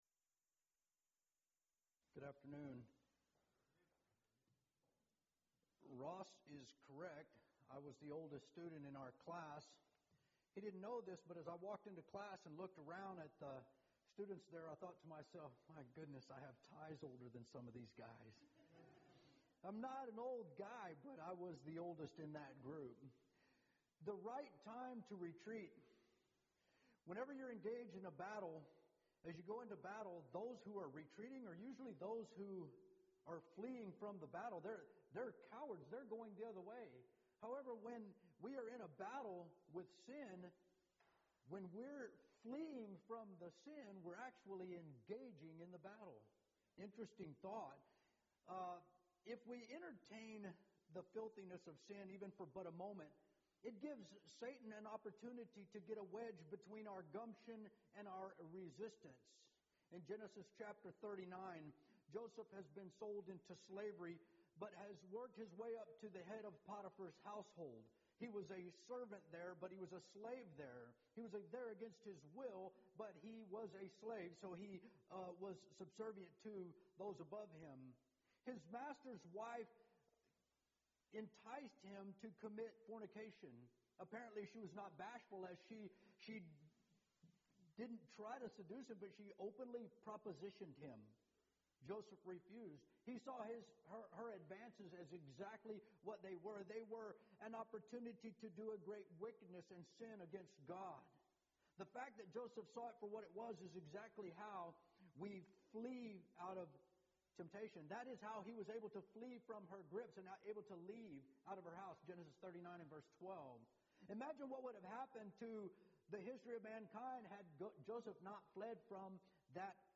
Series: Colleyville Lectures Event: 3rd Annual Colleyville Lectures